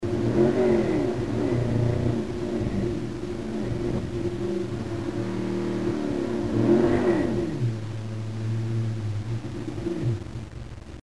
Mustang Exhaust Clips
Mustang Exhaust Sound/Movie Clips Page: